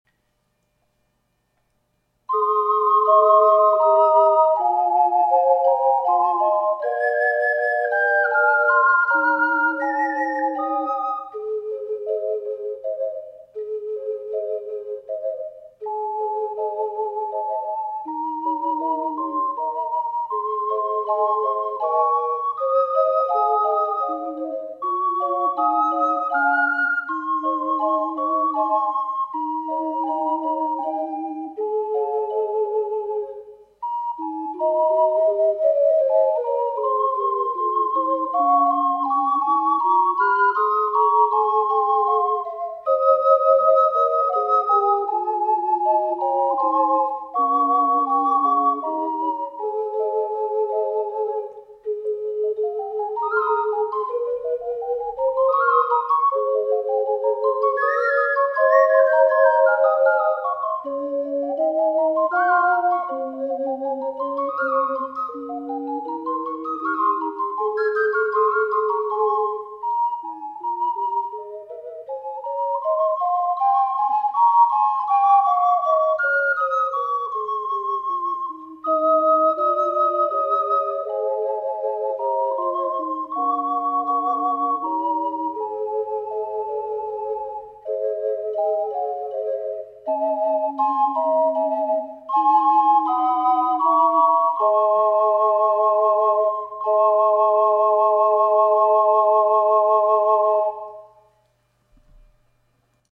④BC     楽譜はヘ長調（Ｆ調：♭1つ）ですが、変イ長調（Ａ♭：♭4つ）に転調しました。
美しいアレンジです。